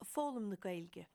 ag foghlaim na Gaeilge ag foghlaim na Gaelainne Dialect Specific ag foghlaim na Gaeilice Note that in any dialect you never pronounce the "g" in "ag" if it's followed by a consonant.